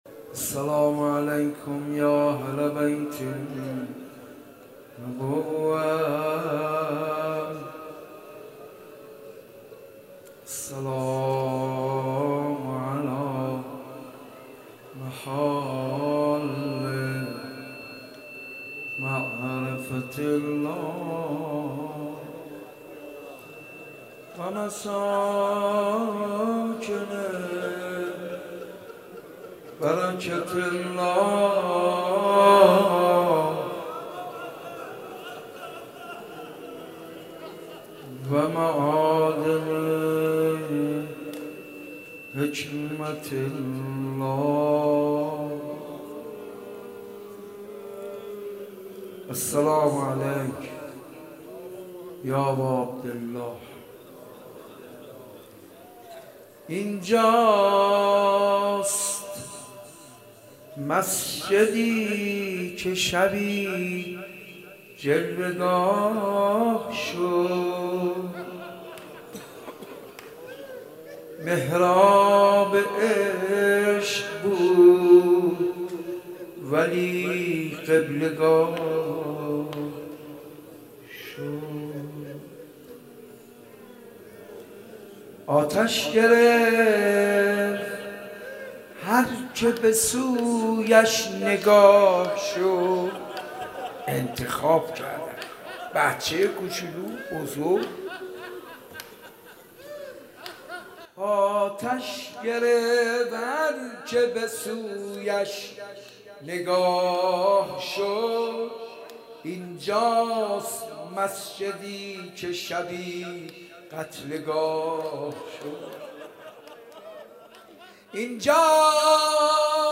مناسبت : شب دوم محرم
قالب : مجلس کامل